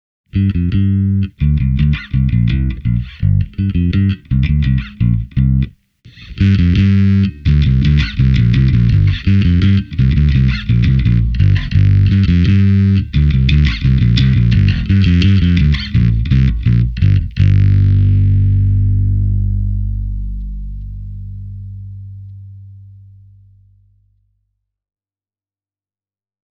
The EBS FuzzMo (current price in Finland: 169,90 €) is a fuzz-type high gain distortion developed especially for bass.
Here’s a bit recorded with a Jazz Bass (both pickups on) and a relatively low Gain setting:
Note that on all these audio clips the Gain control stayed below one o’clock.
ebs-fuzz-mo-e28093-squier-jazz.mp3